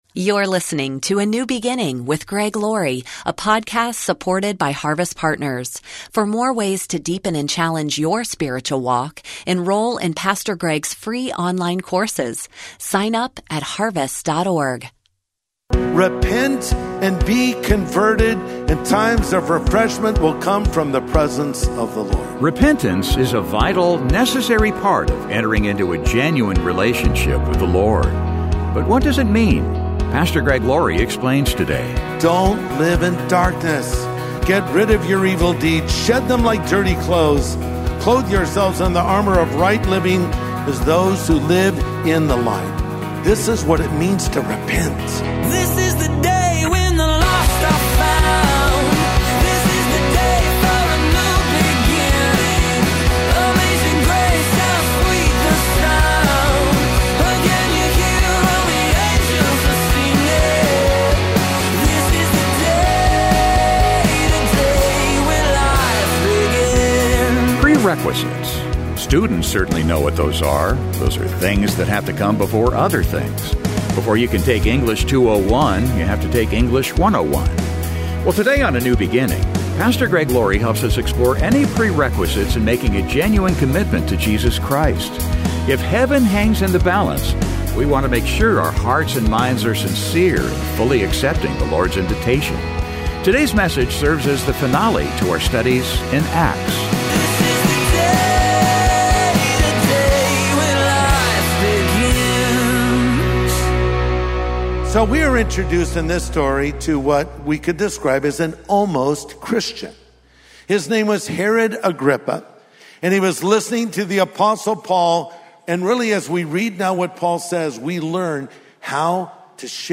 Well today on A NEW BEGINNING, Pastor Greg Laurie helps us explore any prerequisites in making a genuine commitment to Jesus Christ. If heaven hangs in the balance, we want to make sure our hearts and minds are sincere in fully accepting the Lord's invitation. Today's message serves as the finale to our studies in Acts.